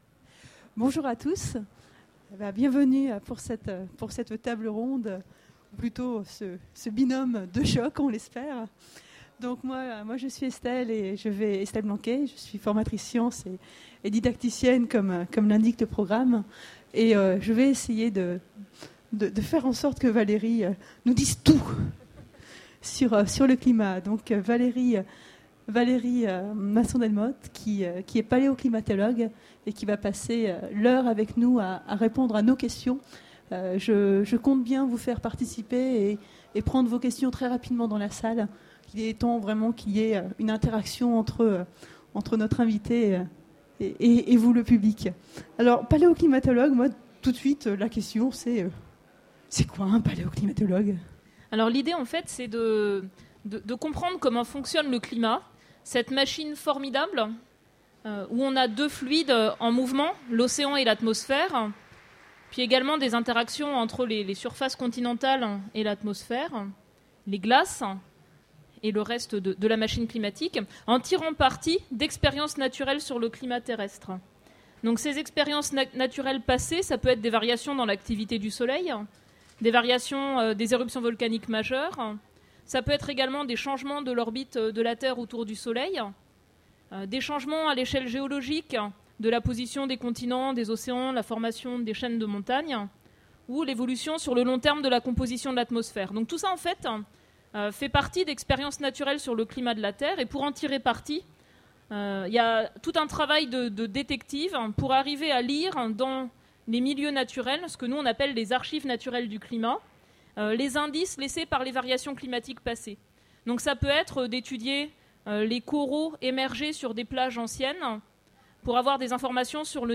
Utopiales 13 : Conférence Quel climat pour le futur ?